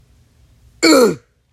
OOF Death Sound
Death Game OOF Roblox sound effect free sound royalty free Gaming